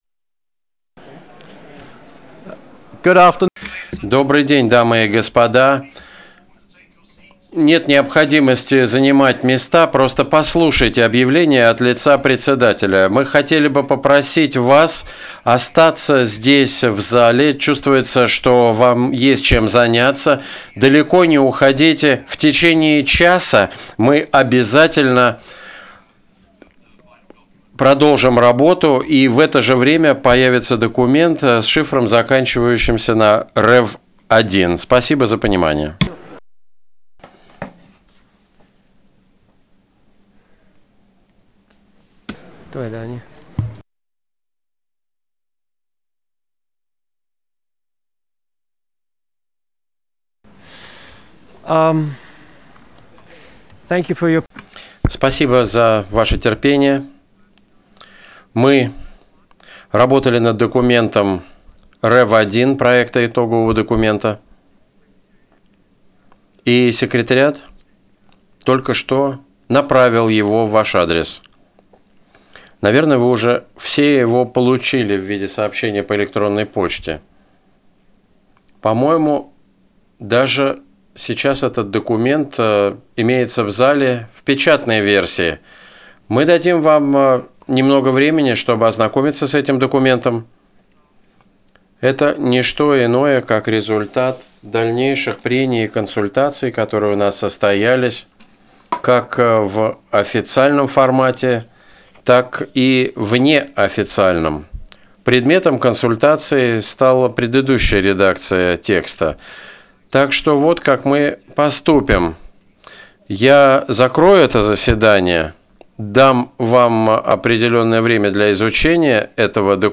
December 15, 2022 15:32 Room XIX
SECRETARY-GENERAL 15:32:25 0:00:16 00:00:21
PRESIDENT 17:31:53 0:00:39 00:03:05
The simultaneous interpretation of the proceedings, including closed captioning, is provided by the United Nations to facilitate communication, in light of the fact that there are six official United Nations languages.